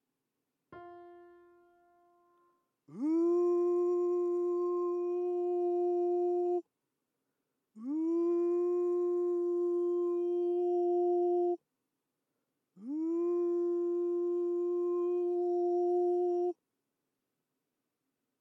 音量注意！
『ウ→オ』の並びを裏声（F4）でデモンストレーションしてみました。